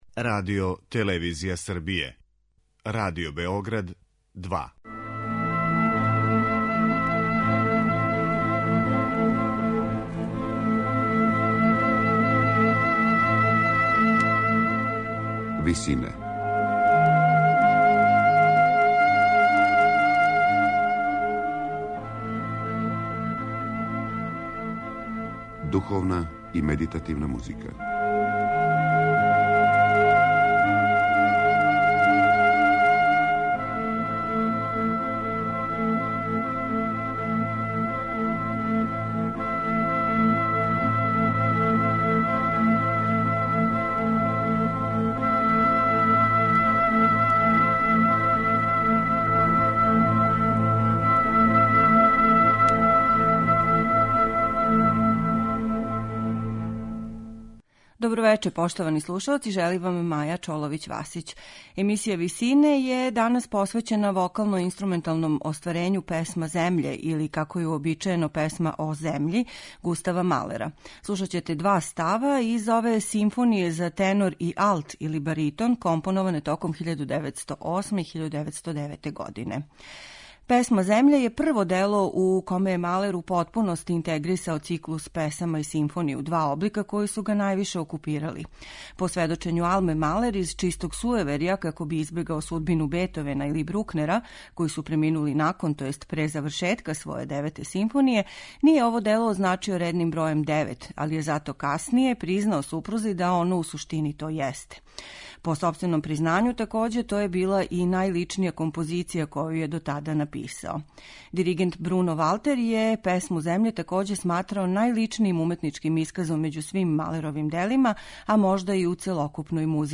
Еисија медитативне музике
У вечерашњој емисији ћете слушати две песме из Симфоније за тенор и алт (или баритон) под називом Песма земље Густава Малера.
Солистиња је Виолета Урмана, а Бечком филхамронијом диригује Пјер Булез.